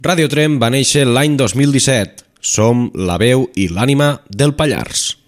Enregistrament fet amb motiu del Dia Mundial de la Ràdio 2022.